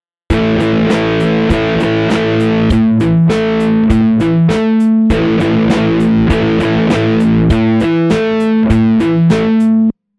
Broken Power Chords
The exact same power chord and rhythm sounds quite different when broken up into individual notes.
The example below demonstrates how different a broken power chord can sound using the same rhythm as another power chord line.
Example 2: Standard power chord, followed by a broken chord version